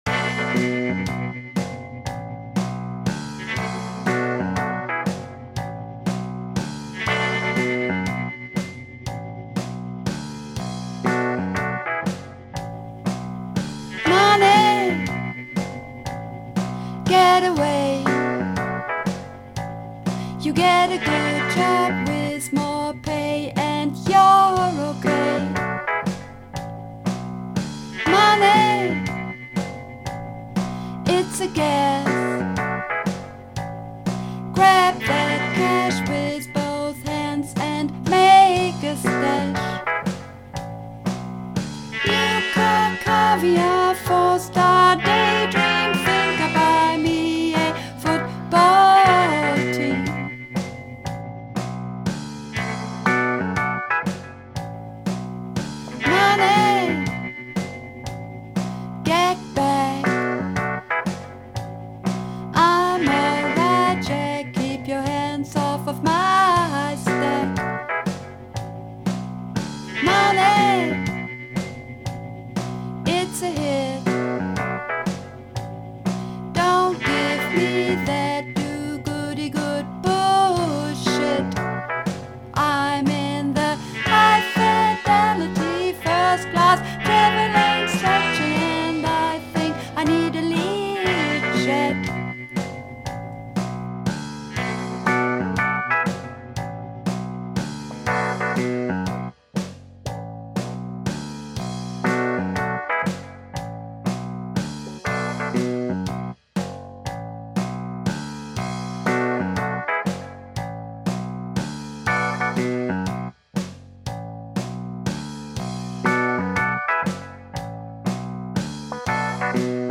Übungsaufnahmen - Money
Runterladen (Mit rechter Maustaste anklicken, Menübefehl auswählen)   Money (Sopran)
Money__2_Sopran.mp3